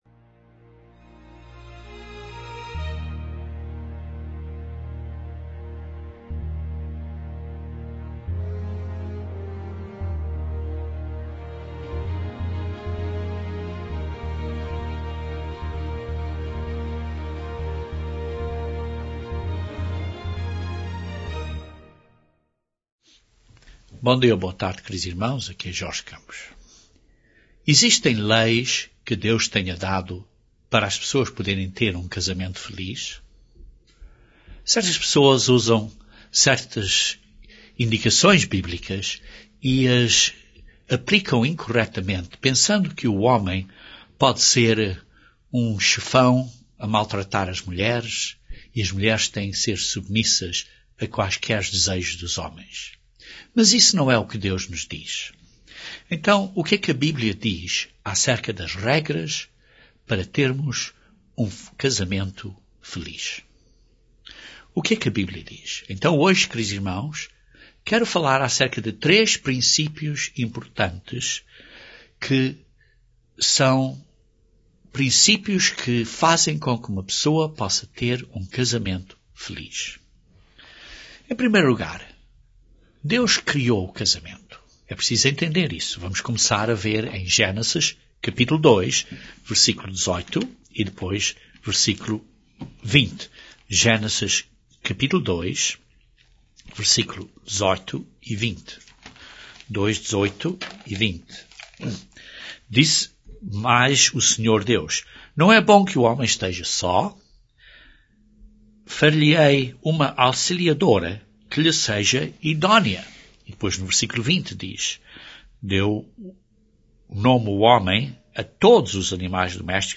Este sermão descreve trêz grandes leis de Deus que governam o matrimónio.